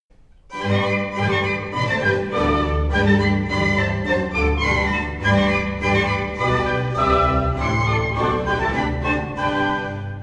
Ejemplo de dos semicorcheas y corchea en una obra para orquesta